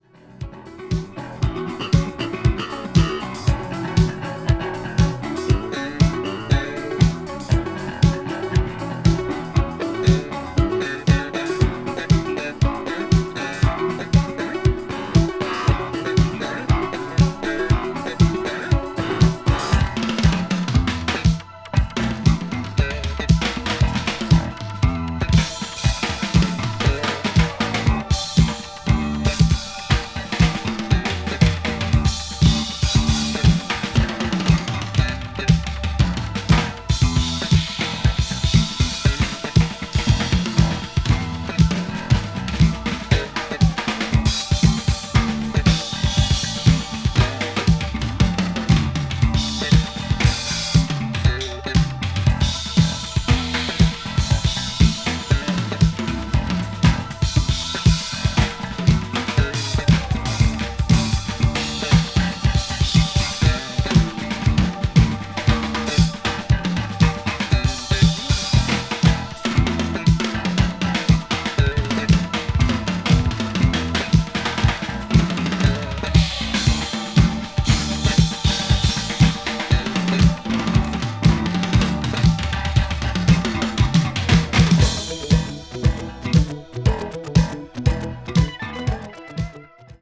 crossover   fusion   jazz groove   obscure dance   synth pop